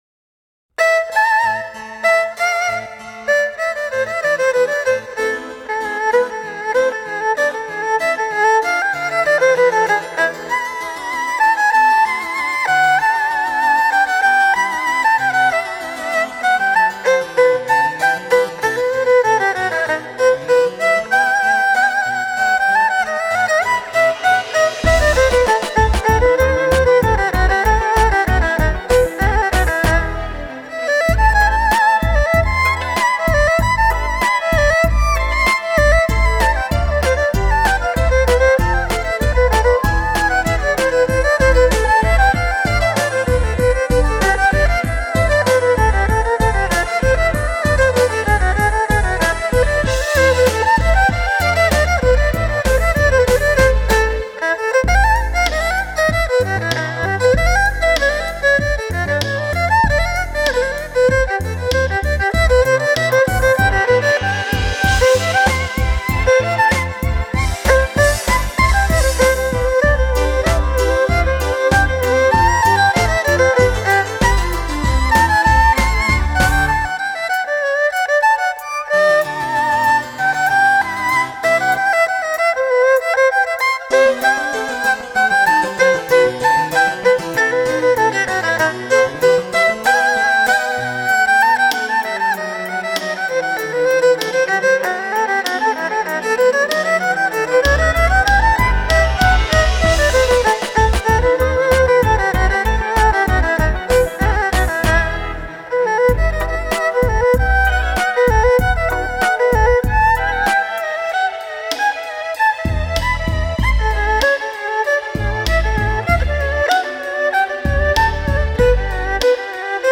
中、西风情，赋予二胡全新的面貌和灵魂，挑战了胡琴的极限！
胡琴来演奏著名的小提琴协奏曲，别有一番风味；第六首的